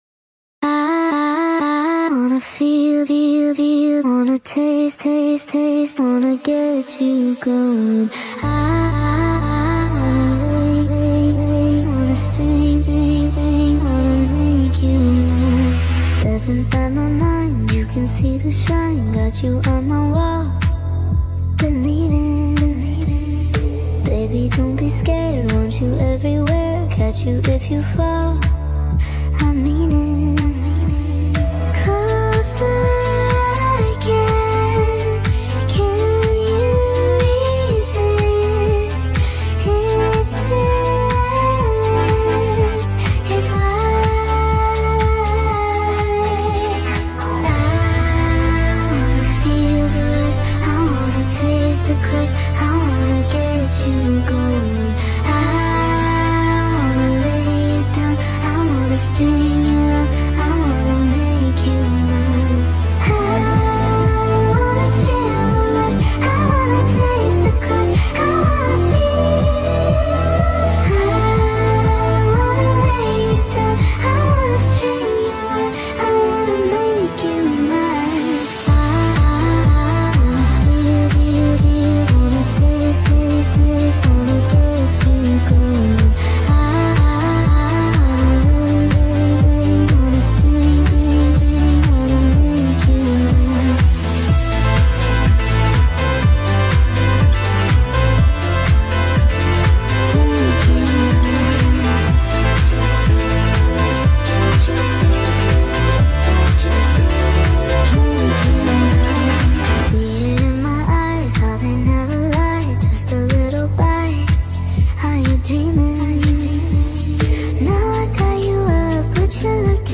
up 3 half steps
key of A Minor